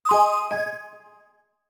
message_alert.ogg